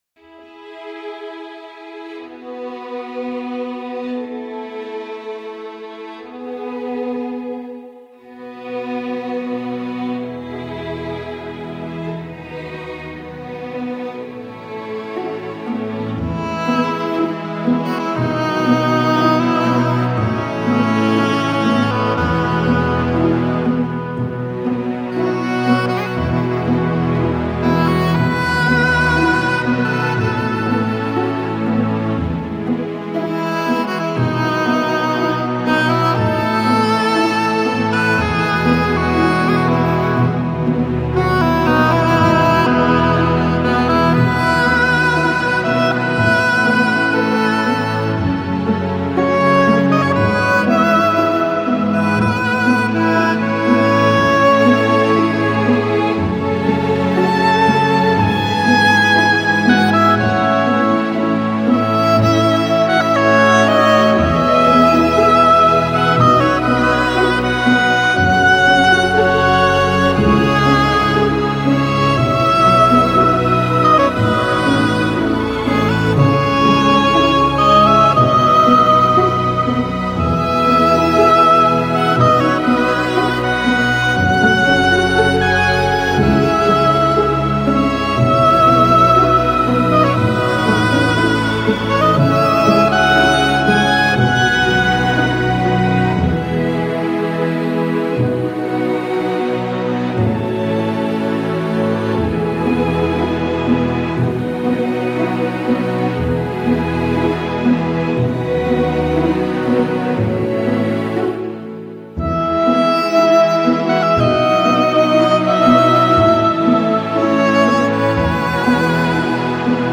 熟悉的旋律重温回忆中的浪漫，悠扬的音符盘旋在寂静的空中。
最出色的十三首作品，首首旋律优美，流畅的吉他、深情的钢琴、感